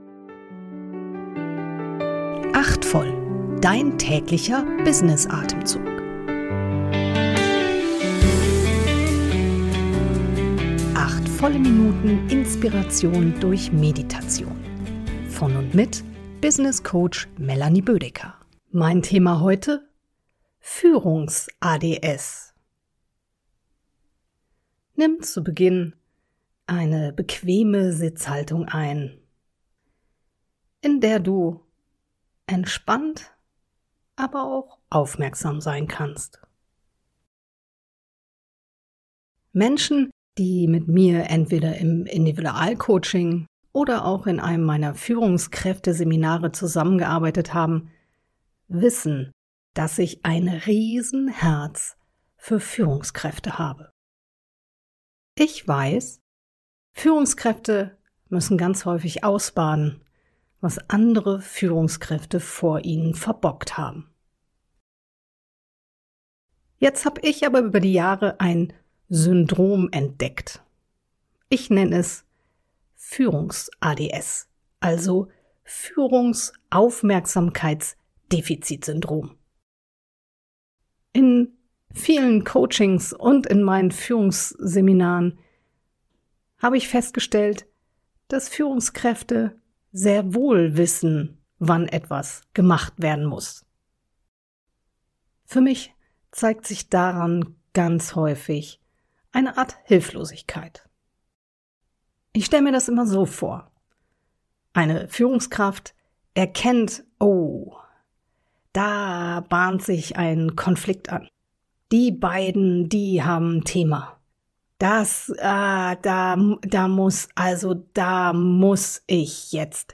eine kurze Erfrischung durch eine geleitete Kurz-Meditation.